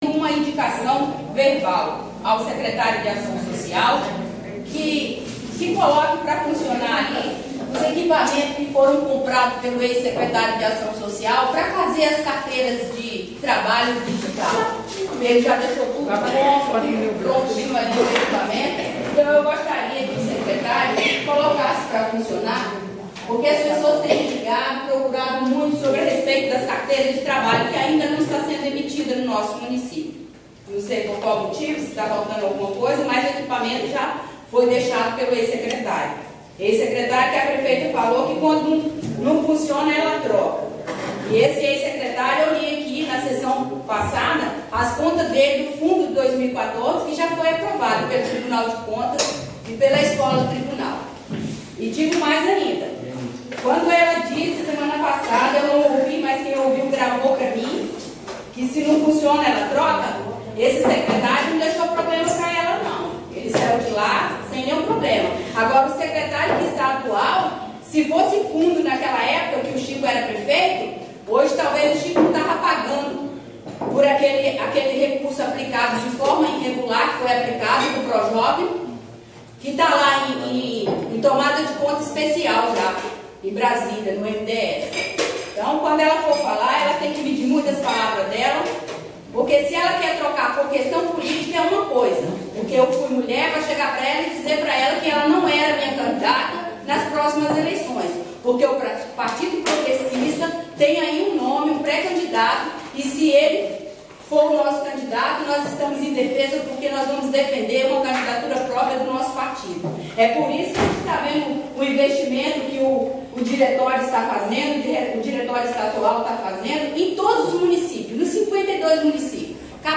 JORGE TEIXEIRA – NALVA FAZ DISCURSO INFLAMADO E DIZ QUE ADMINISTRAÇÃO NÃO ESTÁ BEM COM O POVO
DA REDAÇÃO – O discurso da vereadora Dinalva Laia Ribeiro (Nalva – PP) feito na Tribuna da Câmara de Governador Jorge Teixeira na sessão desta segunda-feira (09) foi diferente do que até então vinha sendo dito pela parlamentar em plenário e, falando como sendo uma ex-aliada da Prefeita Cida do Nenê (PMDB), disse que a administração não vai tão bem como se propaga e que a mesma persegue e tenta confundir adversários políticos fazendo promessas e assumindo compromissos que nunca poderão ser cumpridos.